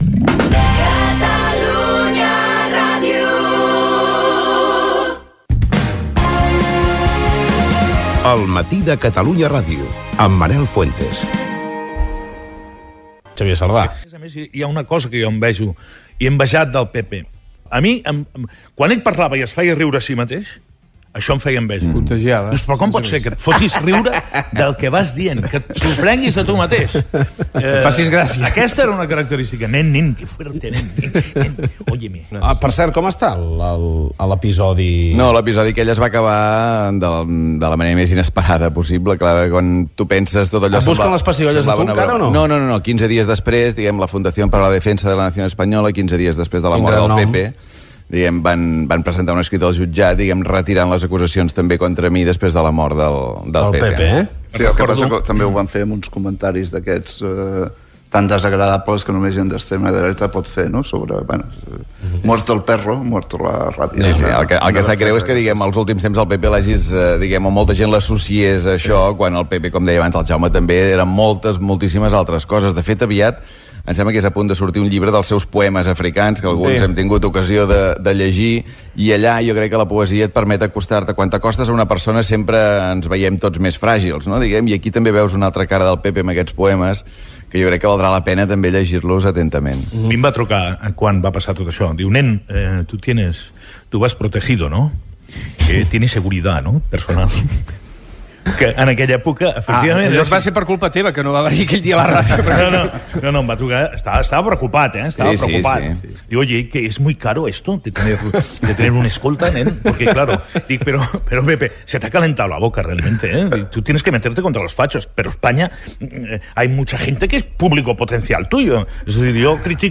Info-entreteniment
Fragment del programa homenatge a Pepe Rubianes, un any després de la seva mort, realitzat des de la Sala Gran del Teatre Capitol.